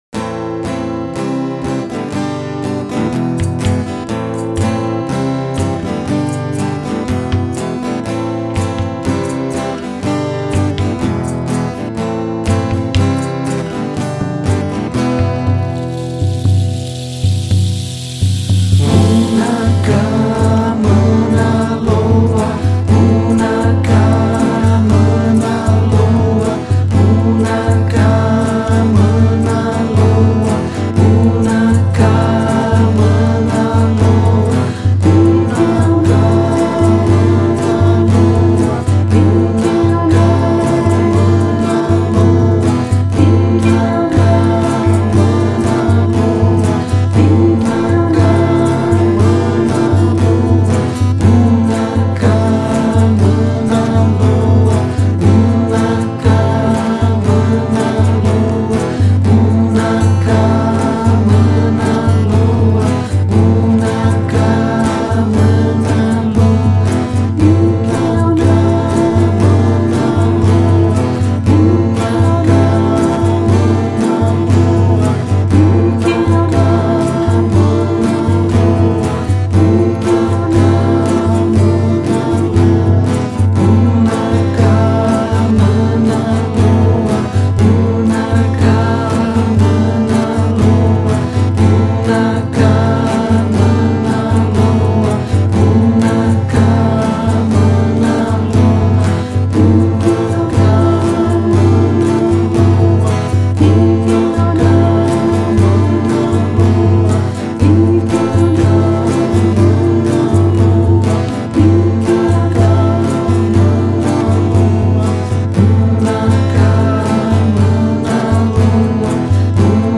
Gravação: GDS Sound – Veranópolis – RS